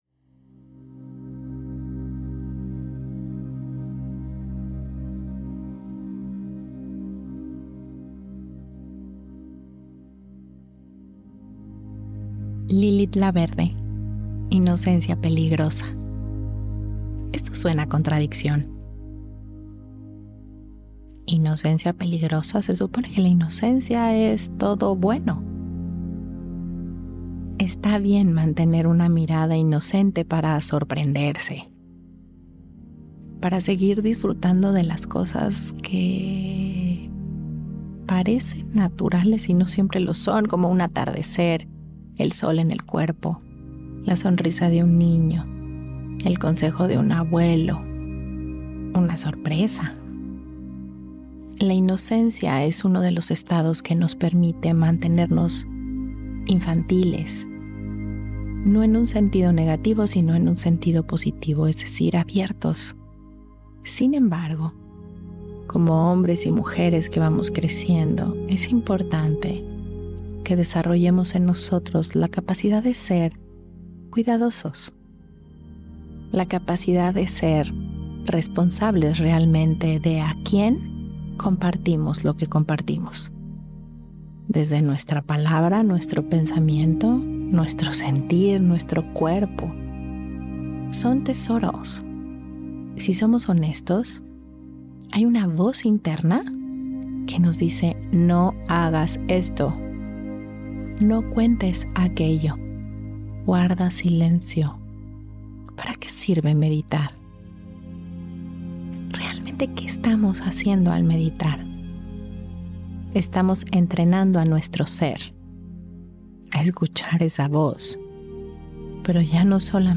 Esta meditación nos invita a reflexionar sobre cómo, cuándo y con quién compartimos lo que somos, sentimos y pensamos.«Yo sé y puedo cuidarme».